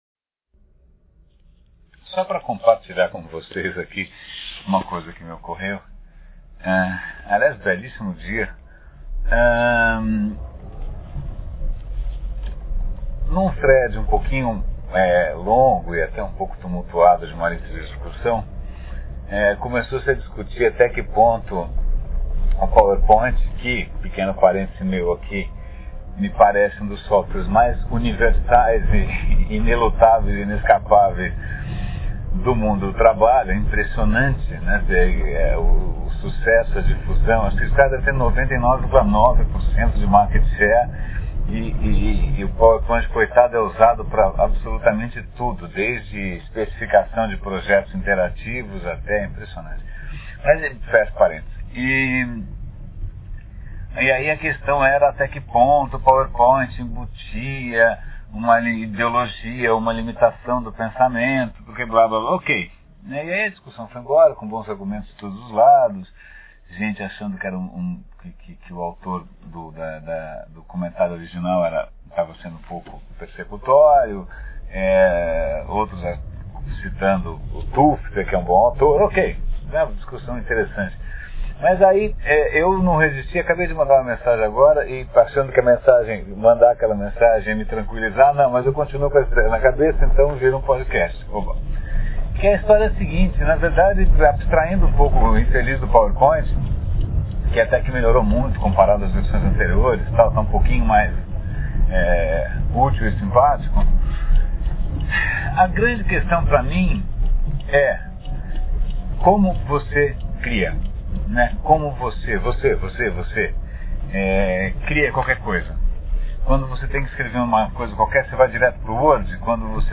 ouça uma reflexão em pleno engarrafamento sobre como não encarcerar o gênio criativo nas garrafas dos softwares autorais.